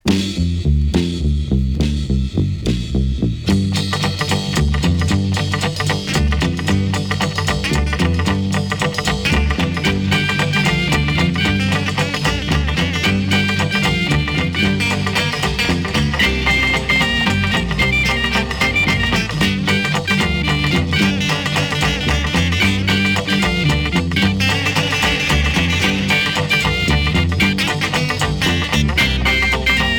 Beat instrumental